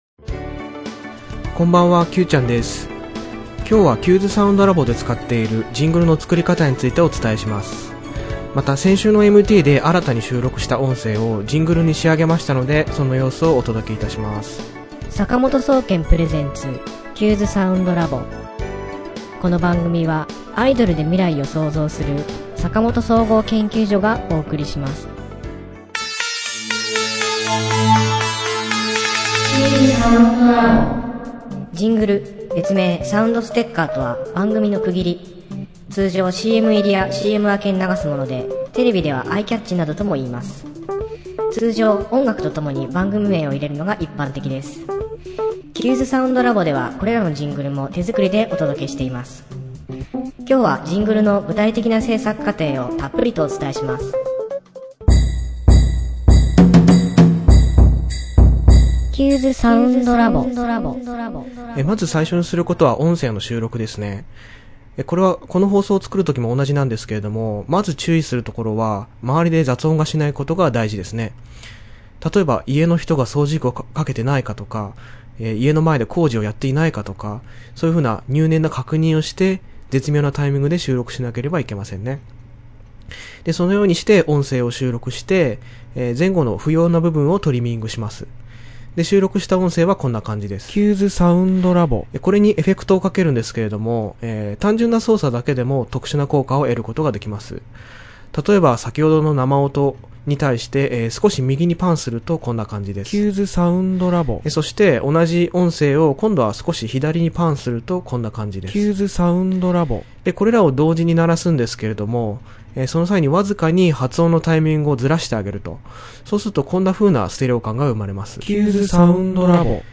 今回はそのジングルの作り方を詳しくお伝えします。また、先週のMT16で収録した音声をジングルに仕上げましたので、新しいジングルをお届けします！